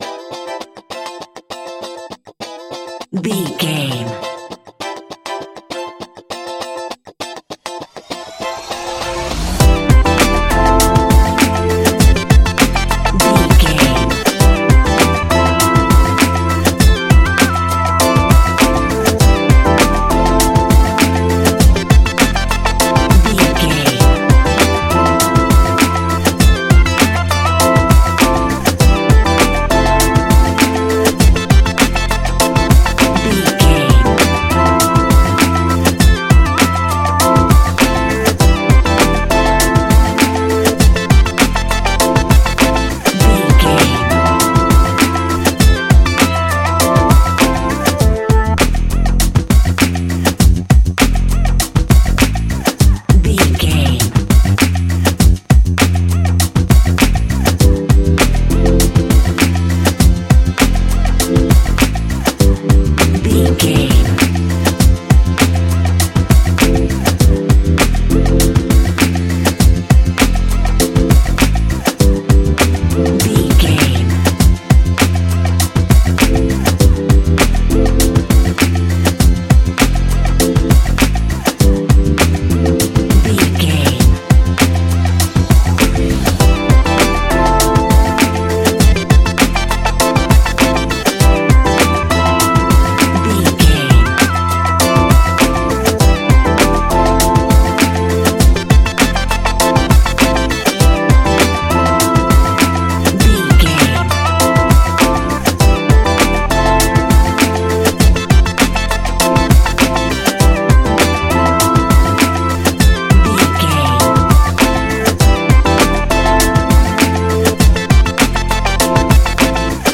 Ionian/Major
chilled
laid back
Lounge
sparse
new age
chilled electronica
ambient
atmospheric
morphing
instrumentals